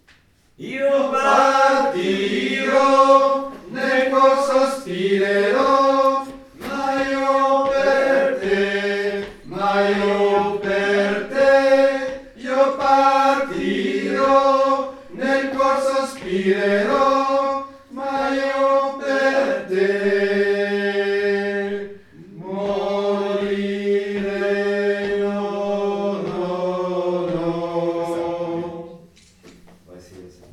Addio_Morettin_Tenor_fin.mp3